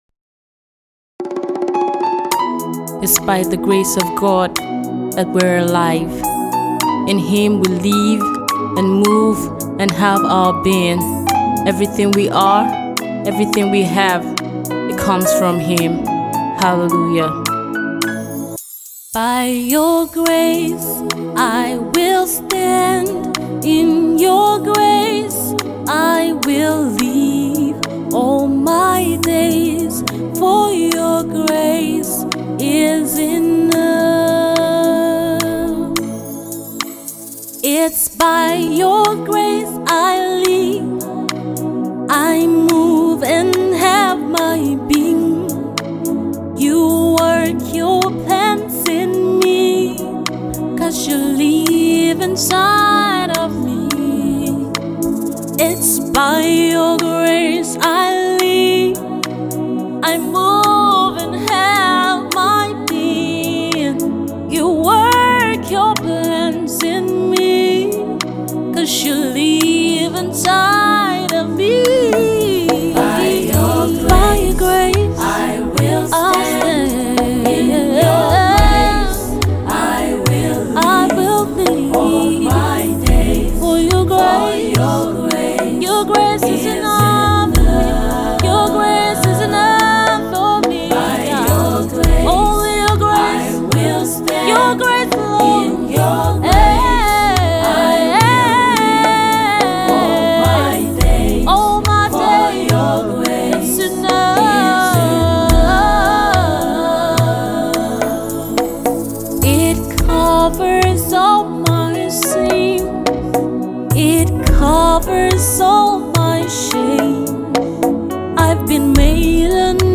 Gospel singer and songwriter